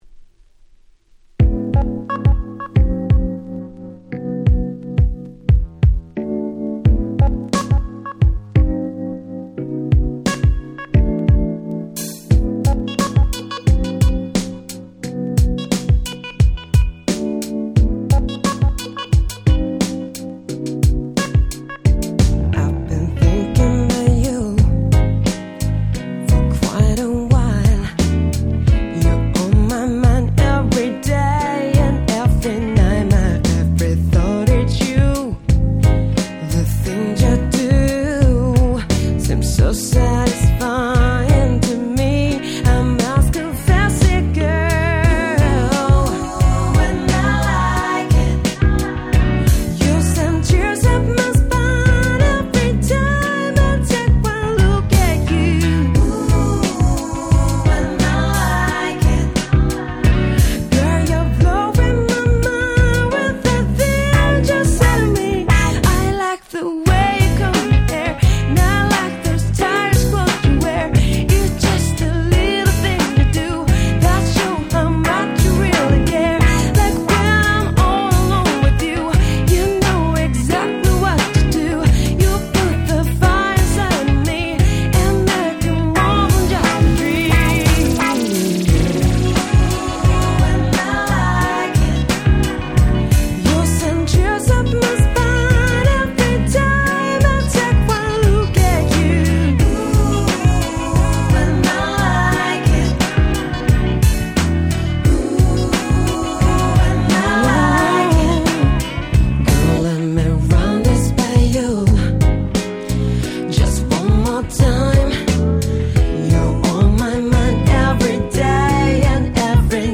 06' Nice Cover Japanese R&B !!